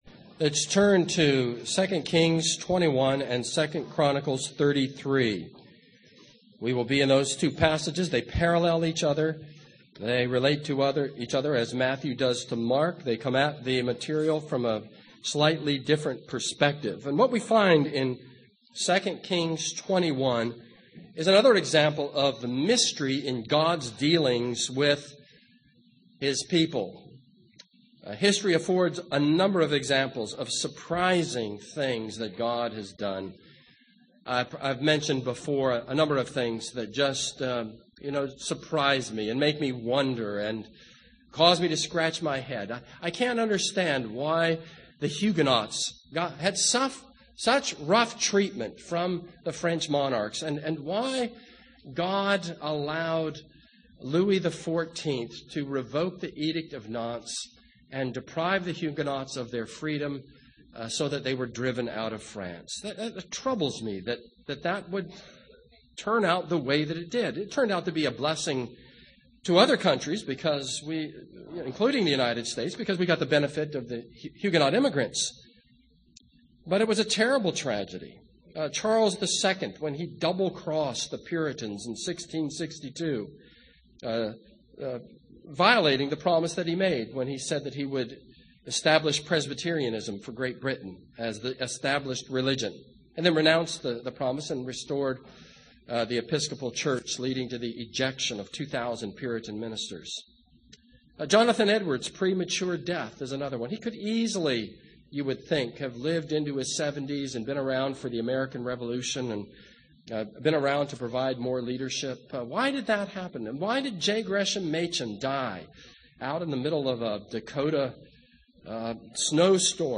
This is a sermon on 2 Kings 20.